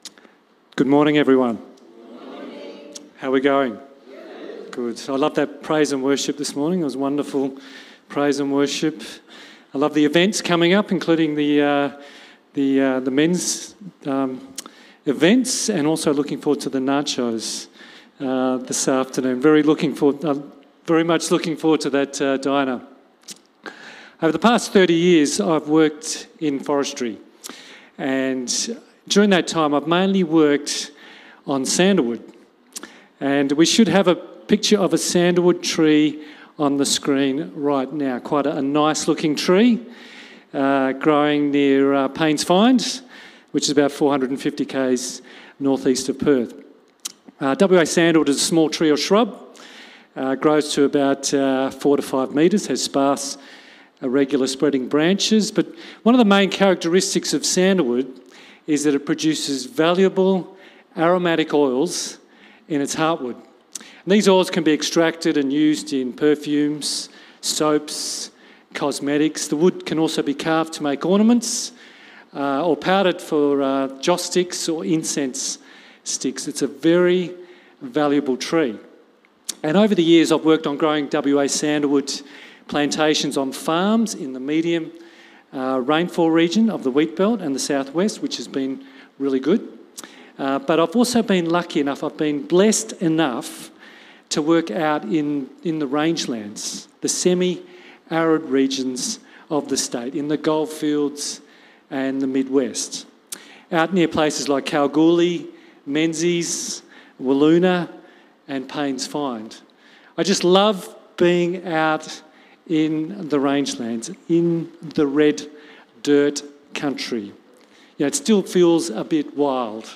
Cityview-Church-Sunday-Service-Living-Waters.mp3